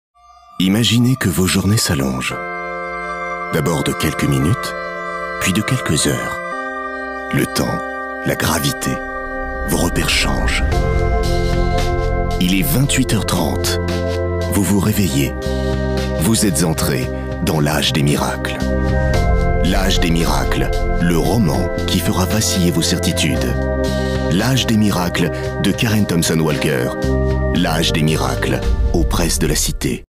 Prestation voix-off inquiétante, élégante et convaincante pour "L'âge des miracles"
Voix inquiétante et envoutante.